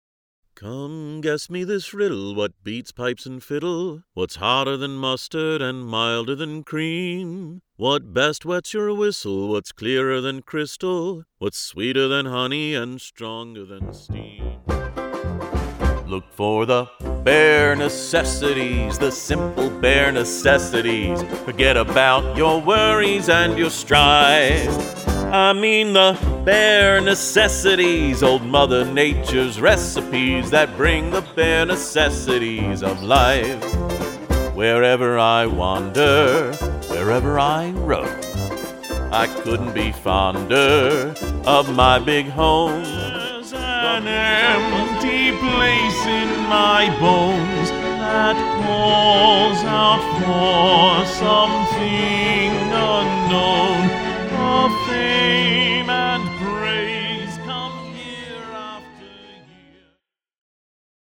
Male
Adult (30-50), Older Sound (50+)
Singing Voice Demo